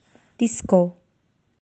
{دیسکو}